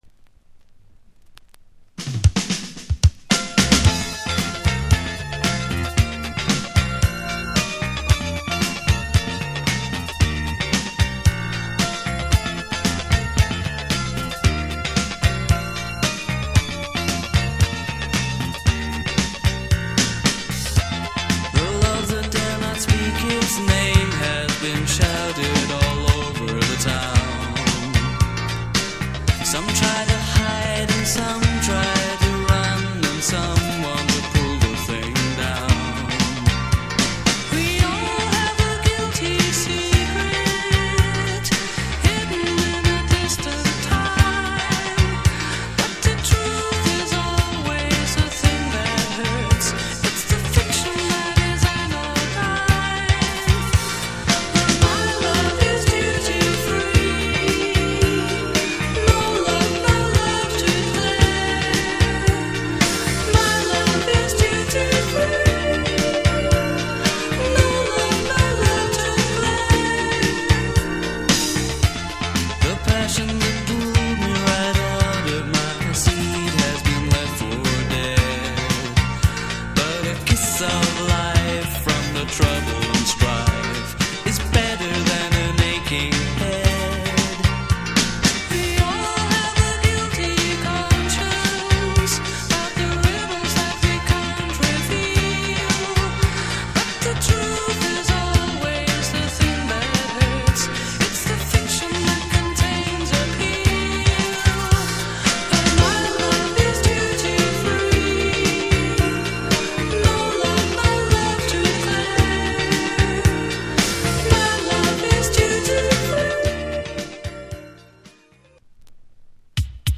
80'S - 90'S RARE NEO ACOUSTIC LISTS  (A-D)